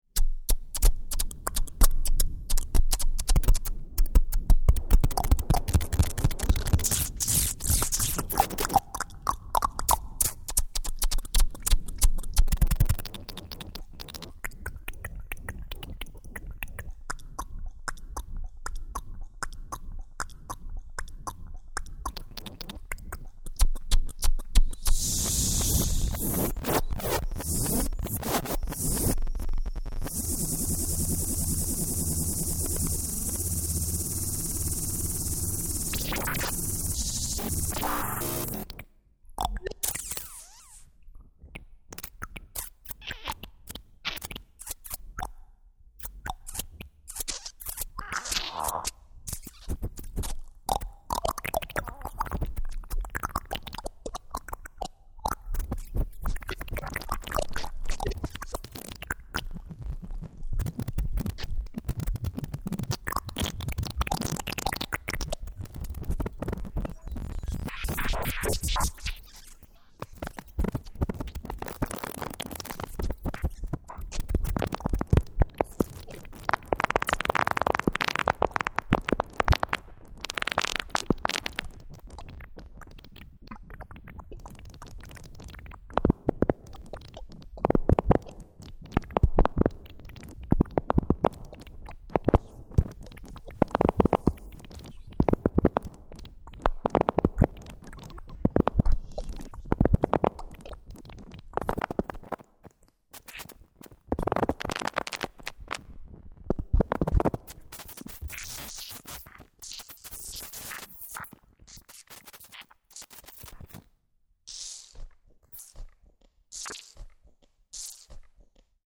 Some old recordings by voice, electronics and self-made instruments.
Voice and Electronics Improvisation (2003) 1.9mb